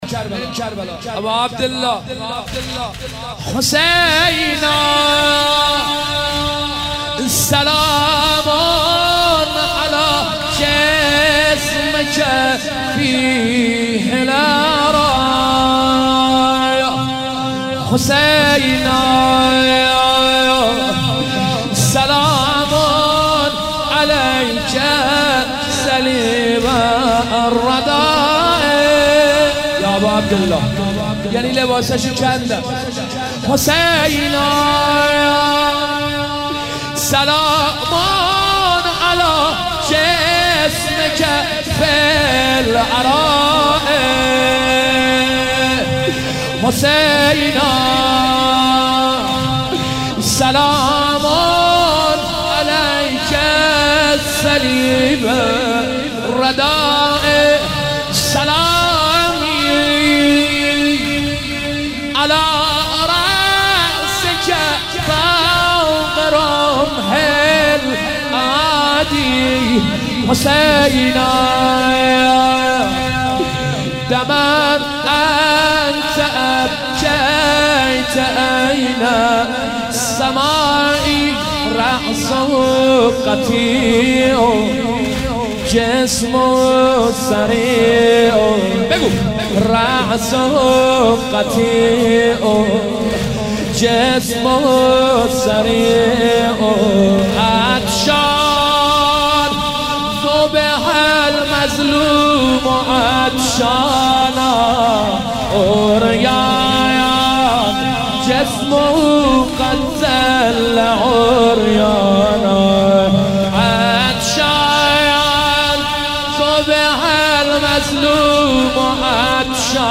مداح
مناسبت : شب بیست و سوم رمضان - شب قدر سوم
قالب : سنگین